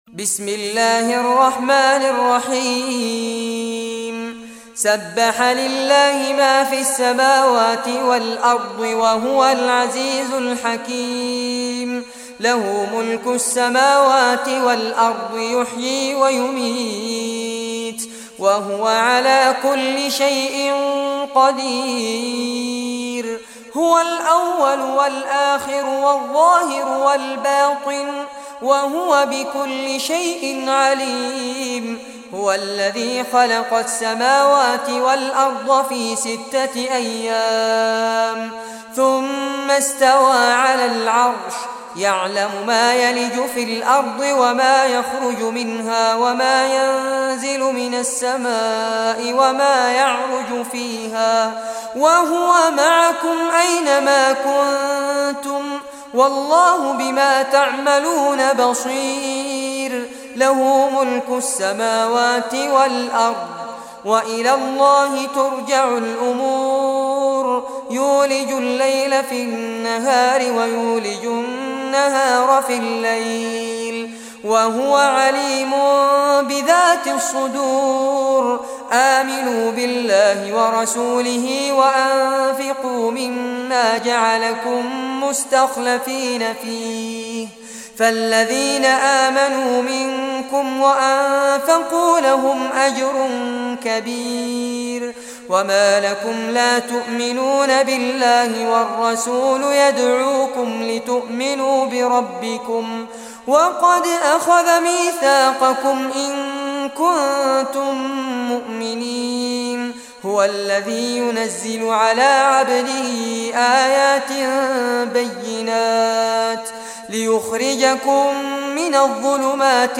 Surah Al-Hadid Recitation by Fares Abbad
Surah Al-Hadid, listen or play online mp3 tilawat / recitation in the beautiful voice of Sheikh Fares Abbad.
57-surah-hadid.mp3